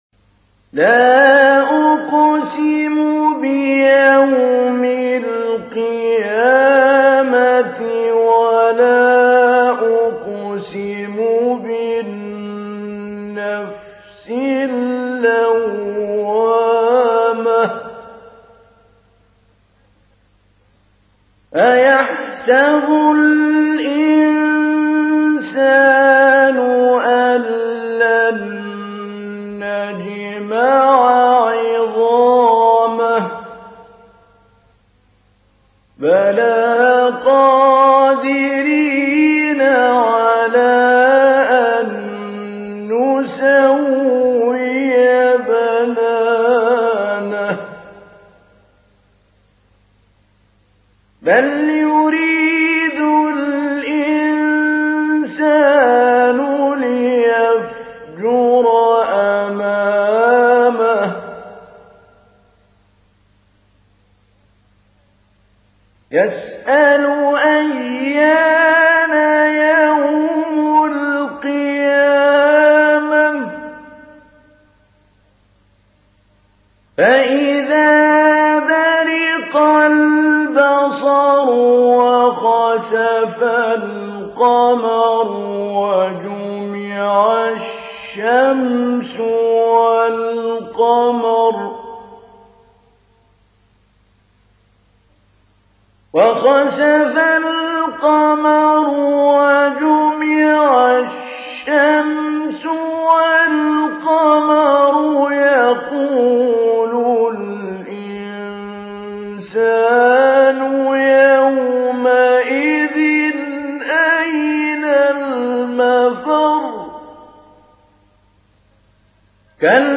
تحميل : 75. سورة القيامة / القارئ محمود علي البنا / القرآن الكريم / موقع يا حسين